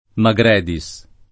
[ ma g r $ di S ]